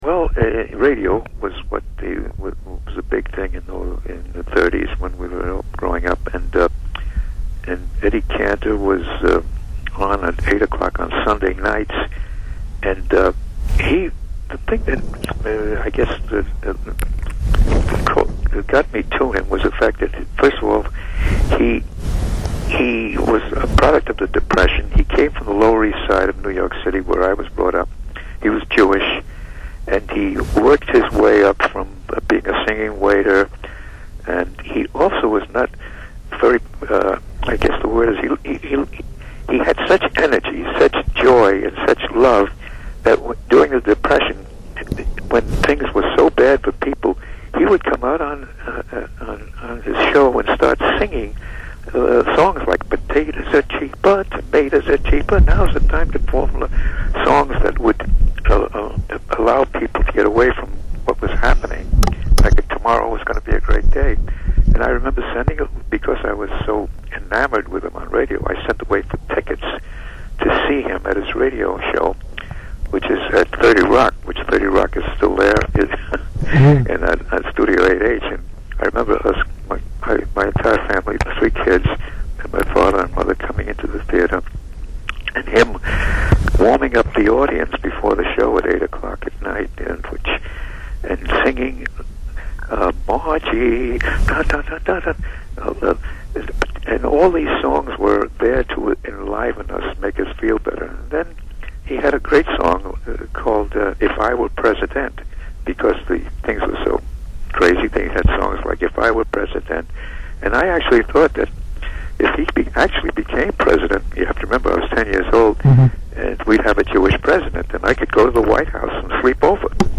Who would have imagined that George Costanza's father and Doug Heffernan's father-in-law would turn out to be such a wonderful, soft-spoken, interesting person?
Interview